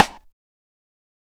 TC3Snare10.wav